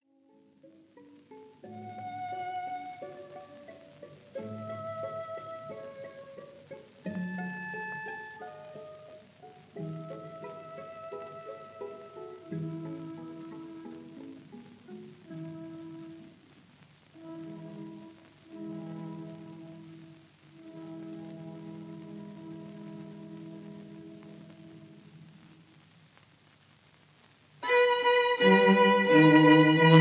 violins
viola
cello
in A major — Adagio